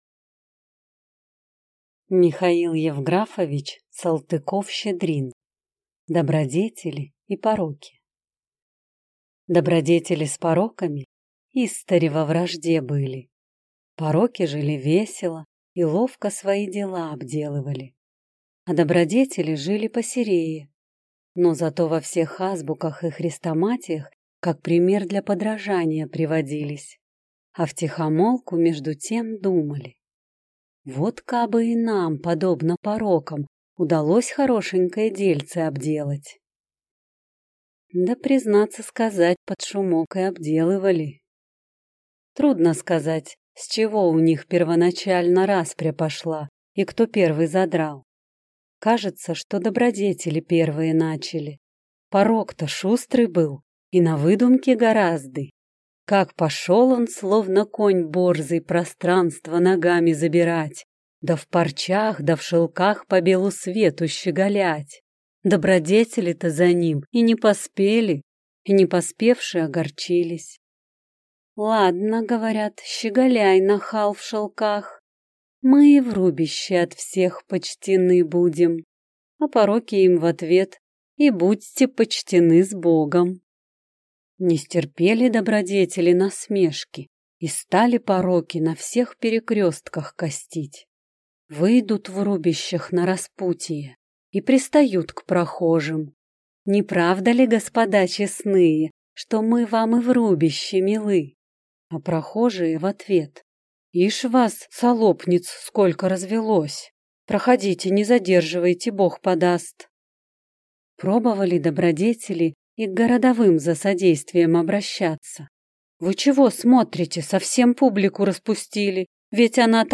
Аудиокнига Добродетели и пороки | Библиотека аудиокниг
Прослушать и бесплатно скачать фрагмент аудиокниги